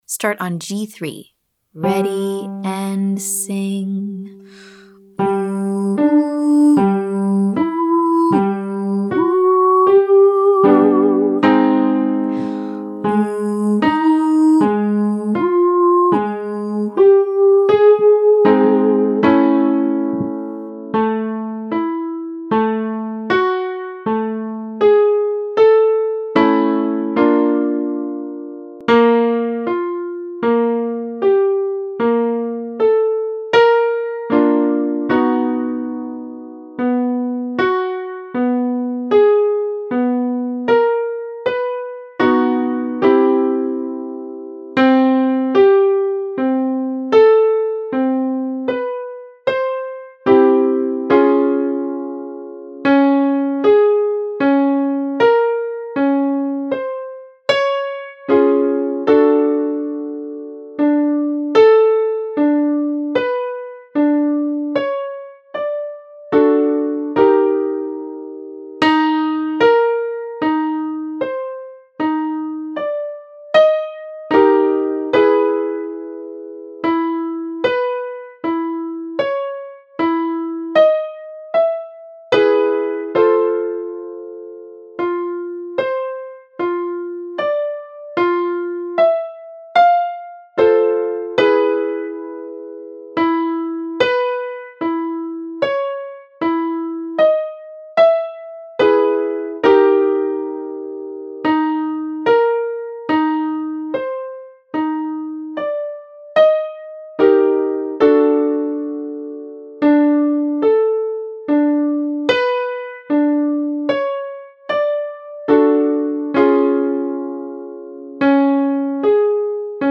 2. Exercise 2: 18 (slow) 181 (faster) – Practice jumping an octave slowly and then quickly.
Daily High Voice Vocal Agility Warmup 3A